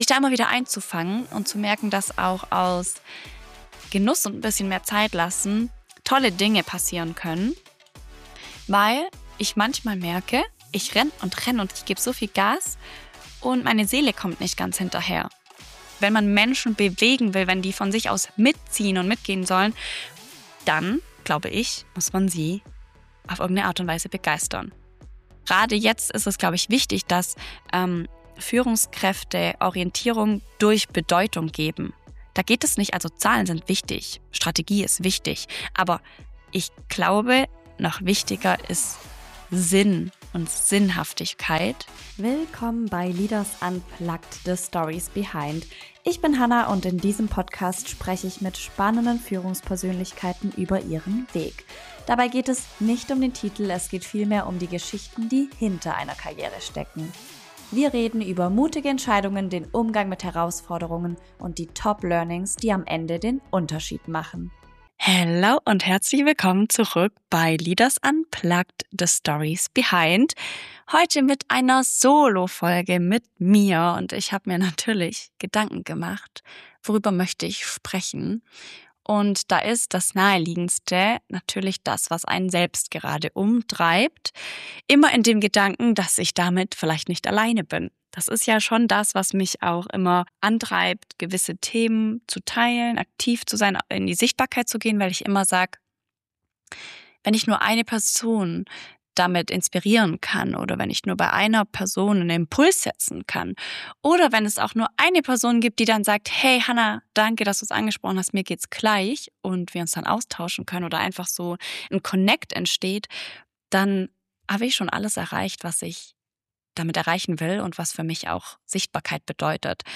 In dieser Solo-Folge teile ich persönliche Gedanken zu Wirksamkeit, Positionierung und der Frage, warum LinkedIn oft nur die Bühne ist.